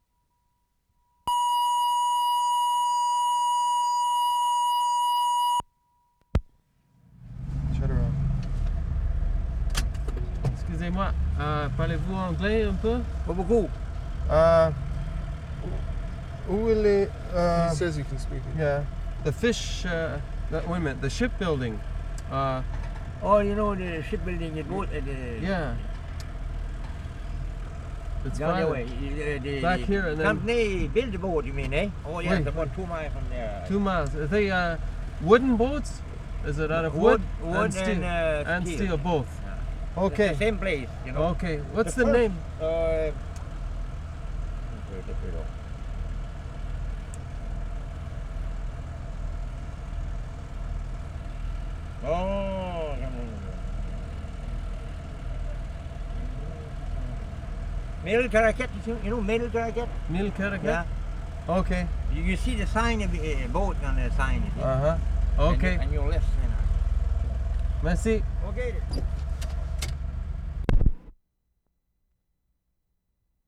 CARAQUET, NEW BRUNSWICK Oct. 27, 1973